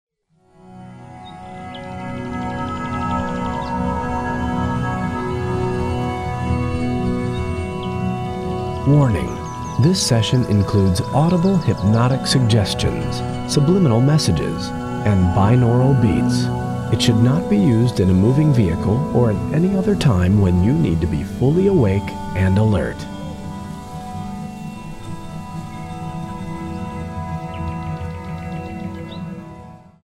healing music,
meditation music,
new age music,
relaxation music,
binaural beats,